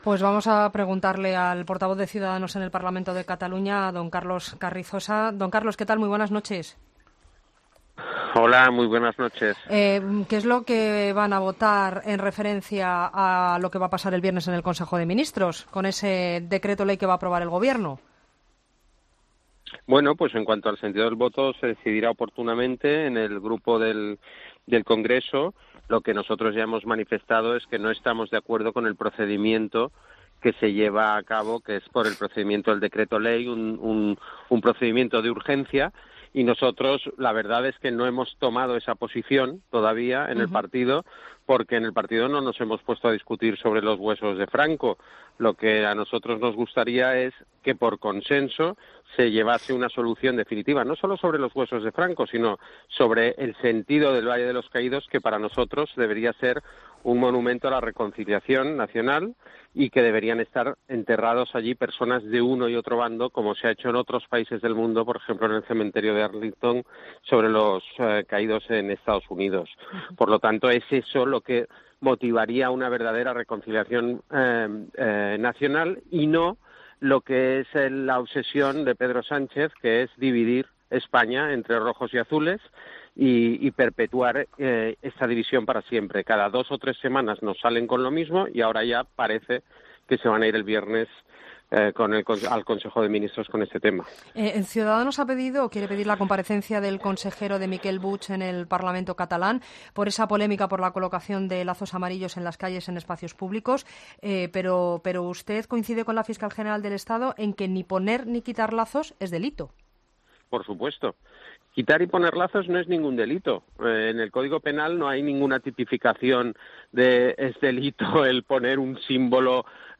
Entrevista a Carlos Carrizosa, portavoz de Ciudadanos en el Parlament, miércoles 22 de agosto de 2018